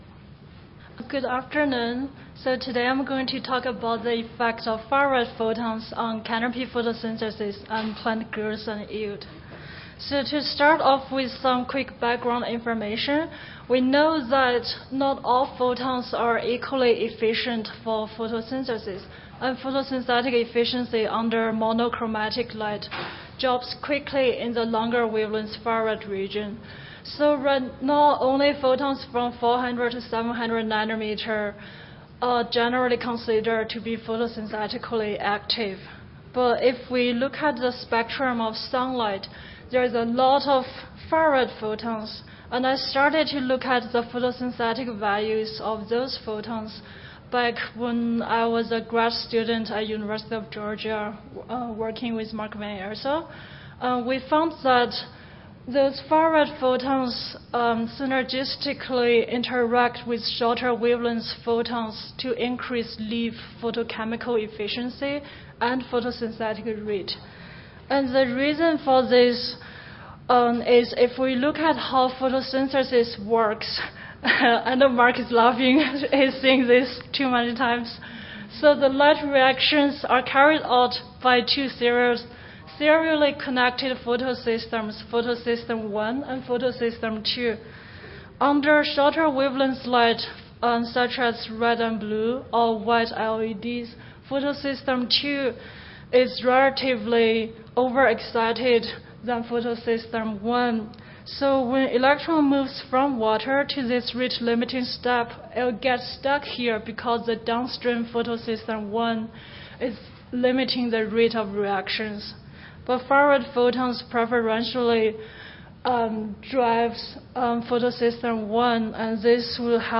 Utah State University Audio File Recorded Presentation